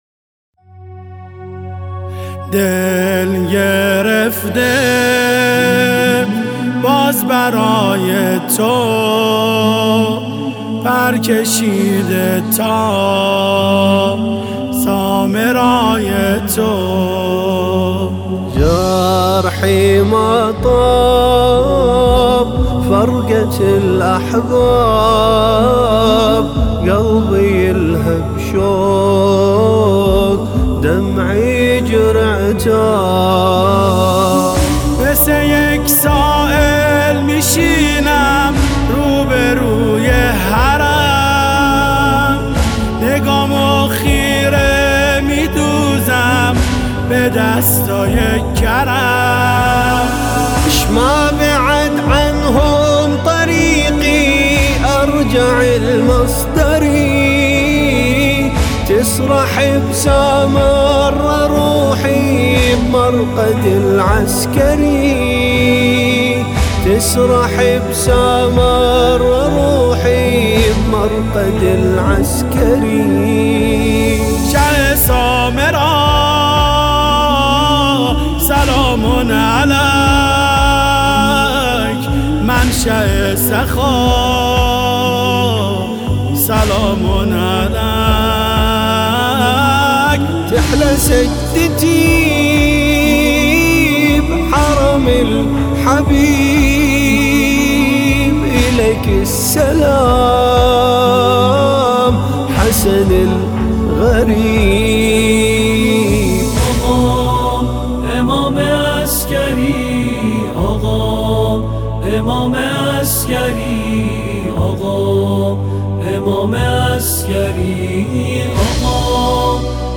مداحی استودیویی